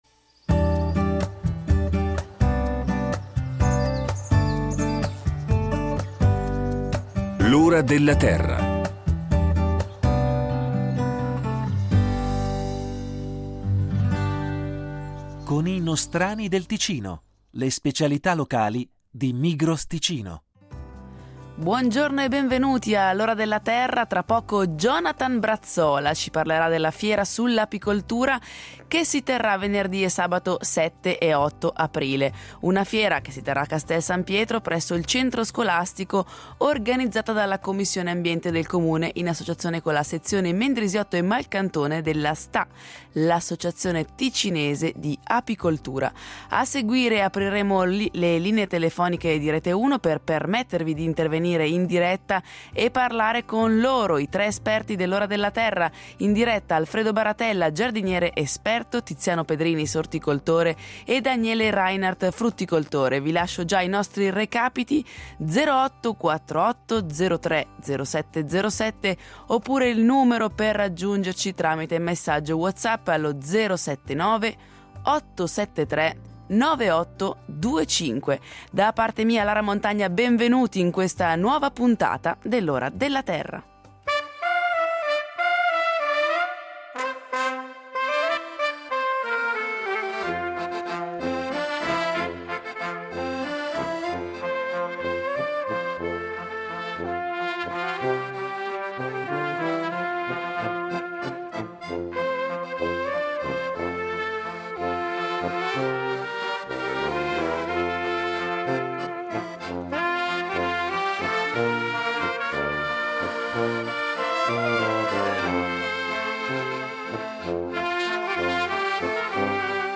per una puntata ampiamente dedicata alle domande del pubblico